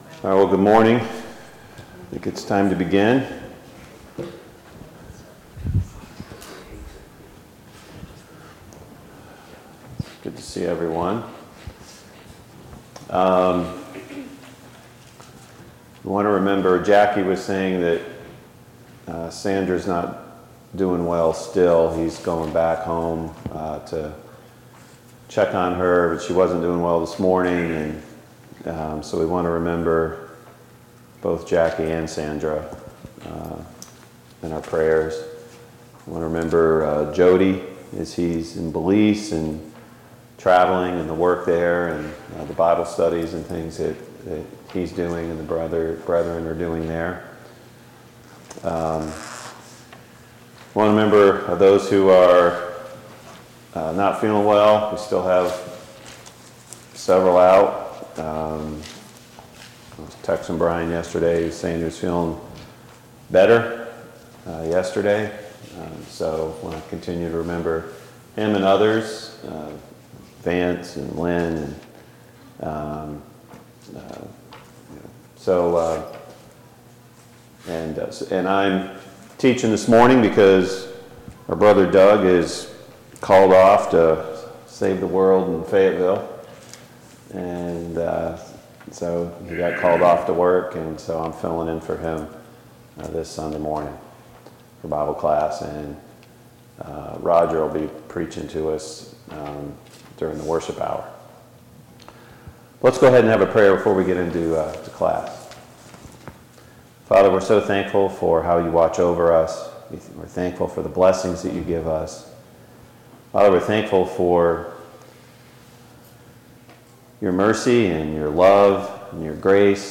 Romans 8:28 Service Type: Sunday Morning Bible Class « Is our focus where it should be?